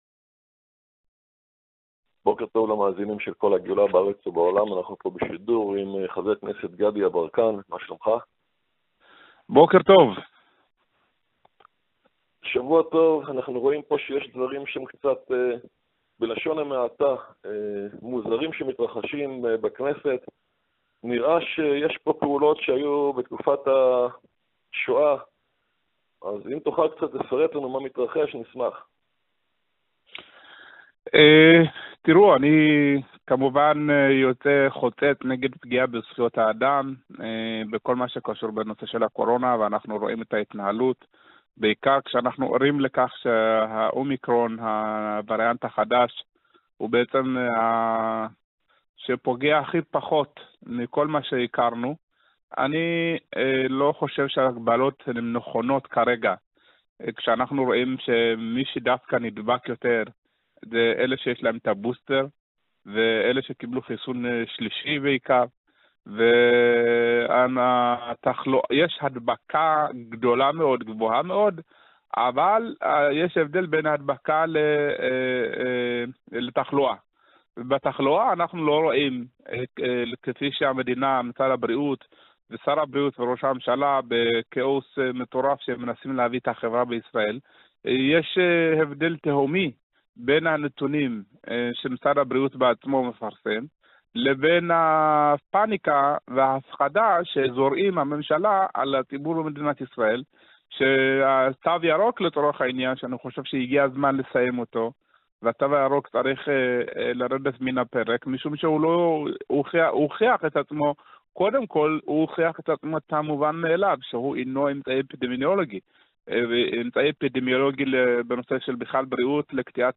מראיין